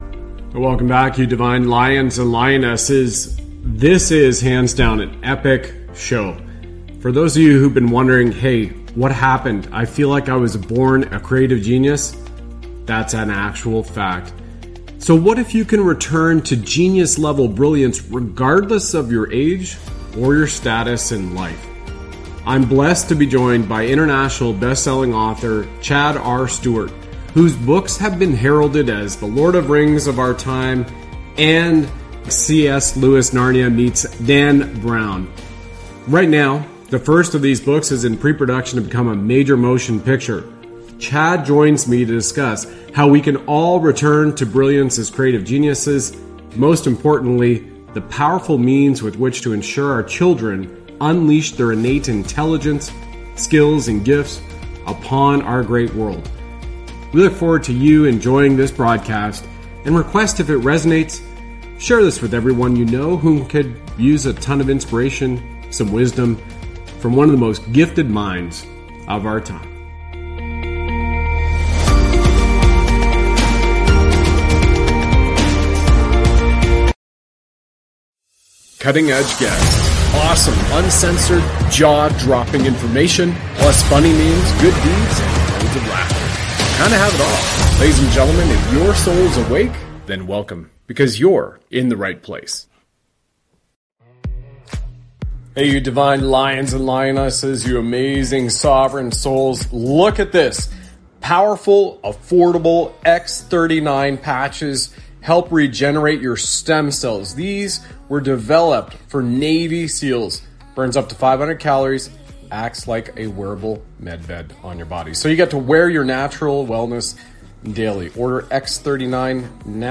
This broadcast features a discussion